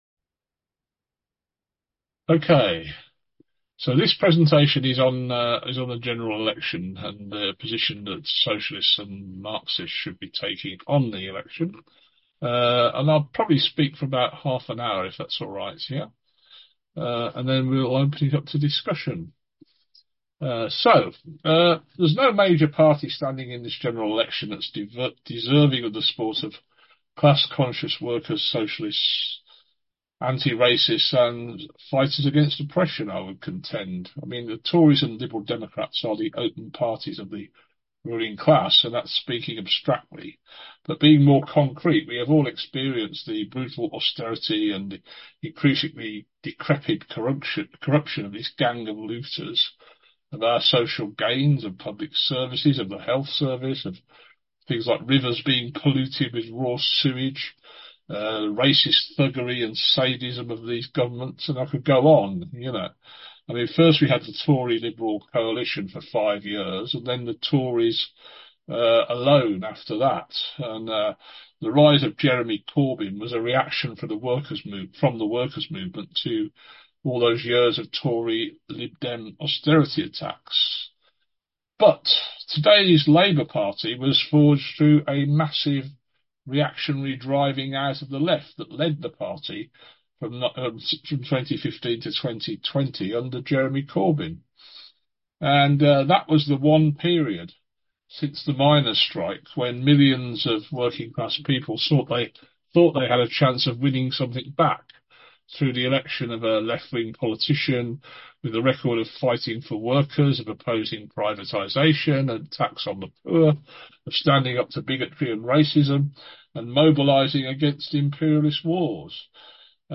The presentation and discussion at our forum this afternoon can also be heard here as a podcast